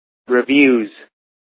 Uttal
Uttal US Okänd accent: IPA : /vjuːz/ Ordet hittades på dessa språk: engelska Ingen översättning hittades i den valda målspråket.